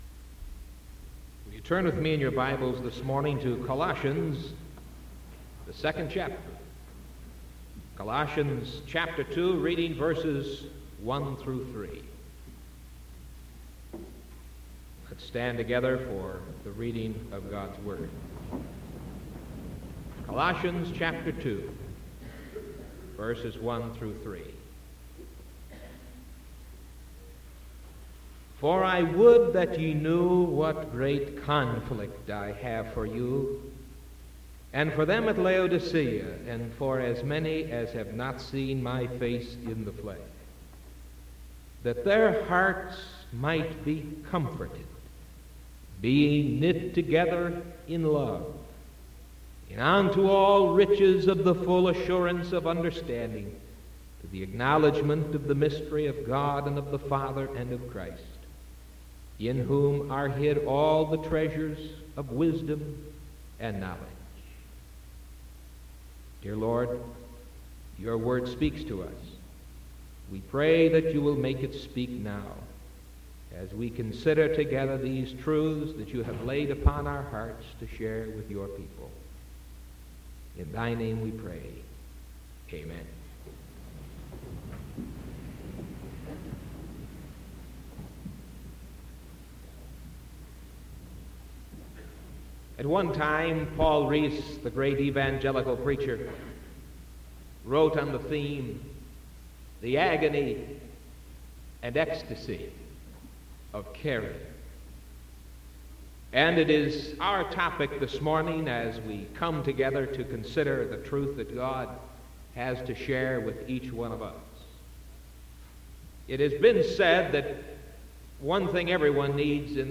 Sermon June 9th 1974 AM